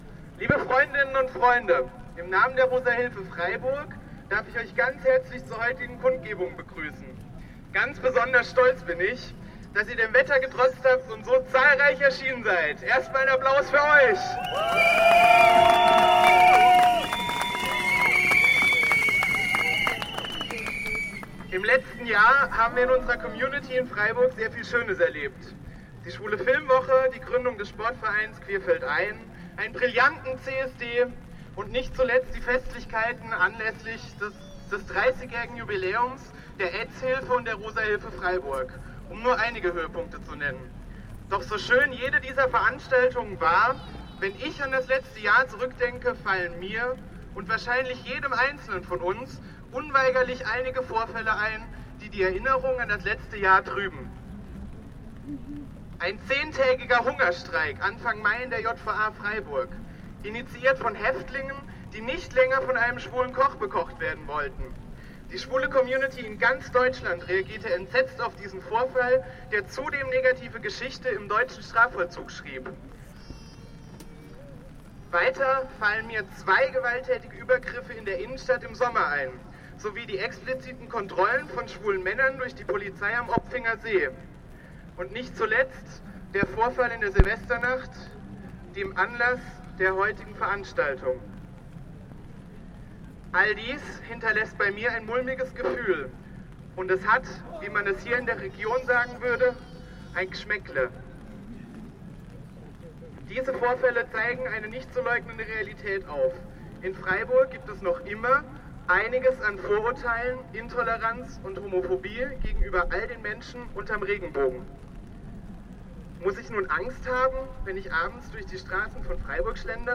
In strömenden Regen bewegten kurz nach 18 Uhr vom Versammlungsort vor dem Theater - nicht ohne OB-Adresse - bis zu über 800 vornehmlich junge Leute auf einen eher verschwiegenen Marsch über Bertholdsbrunnen- Kajo - zurück zur...